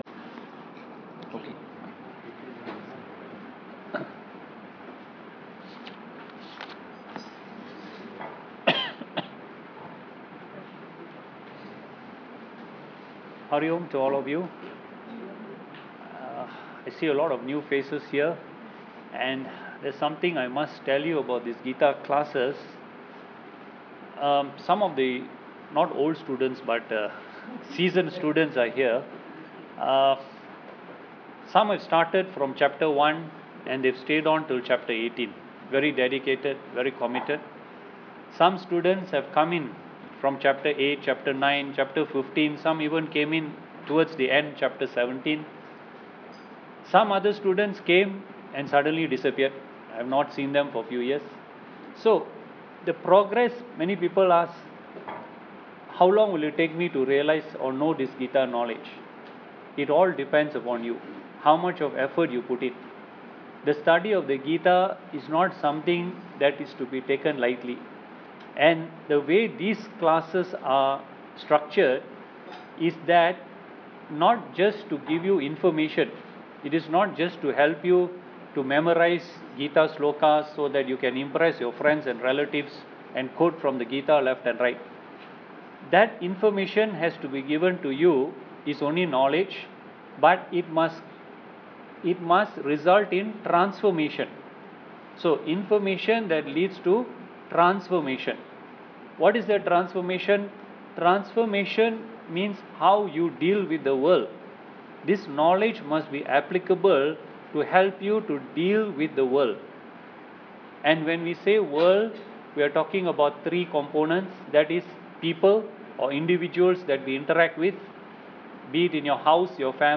Here is an audio clip of our 1 hour lecture with him last Thursday 🙂